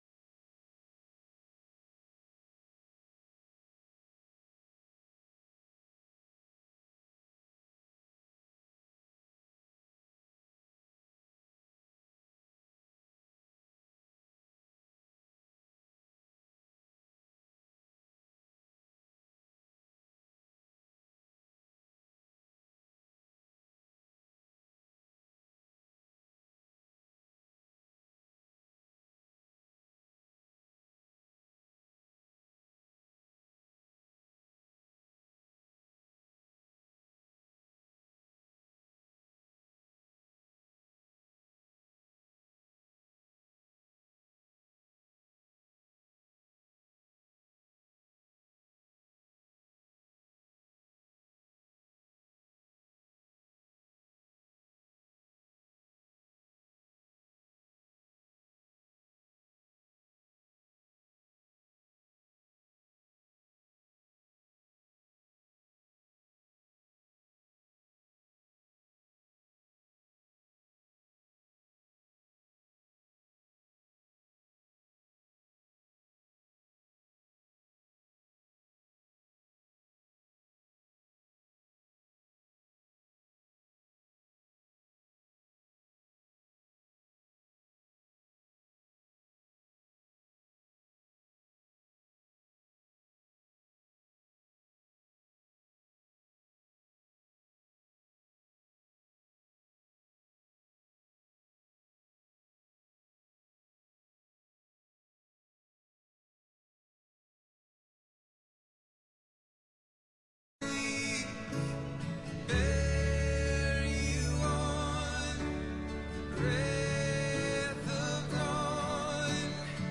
4_11-21-Sermon.mp3